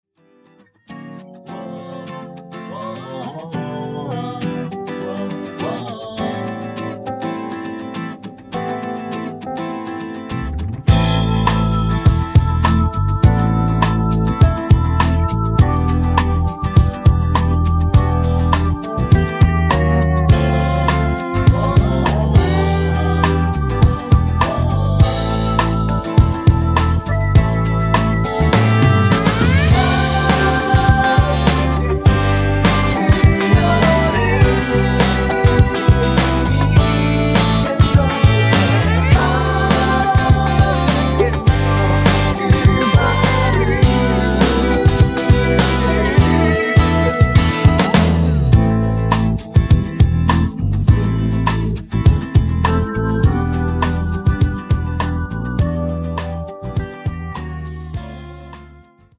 음정 원키 3:07
장르 가요 구분 Voice MR